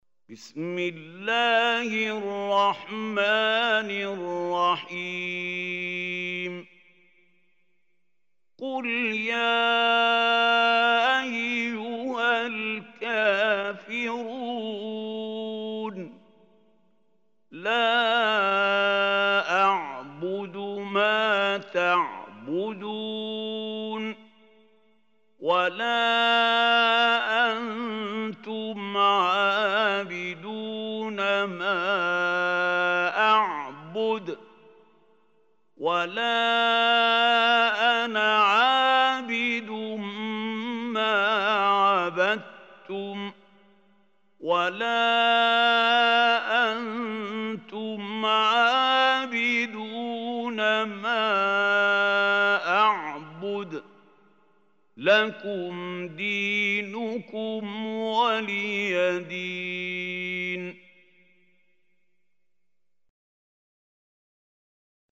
Surah Al-Kafirun Recitation by Mahmoud Hussary
Surah Al-Kafirun is 109 surah of Holy Quran. Listen or play online mp3 tilawat / recitation in Arabic in the beautiful voice of Sheikh Mahmoud Khalil Hussary.